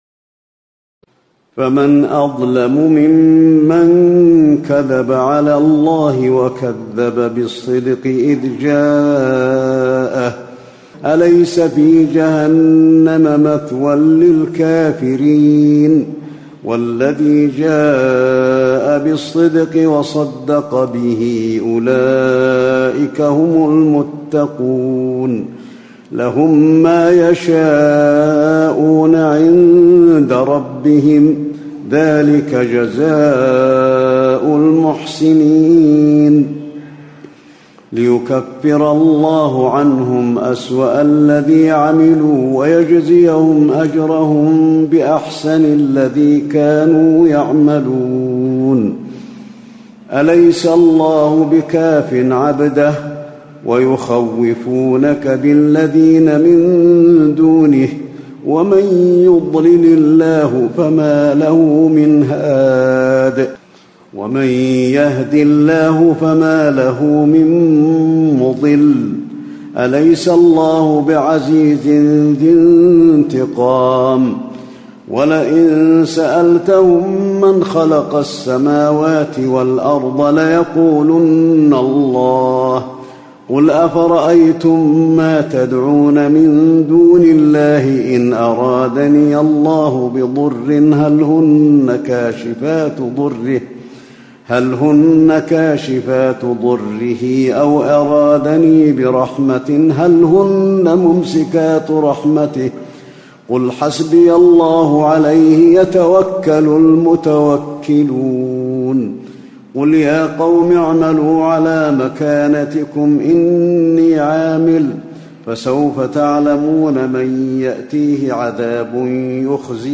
تراويح ليلة 23 رمضان 1437هـ من سور الزمر (32-75) و غافر (1-39) Taraweeh 23 st night Ramadan 1437H from Surah Az-Zumar and Ghaafir > تراويح الحرم النبوي عام 1437 🕌 > التراويح - تلاوات الحرمين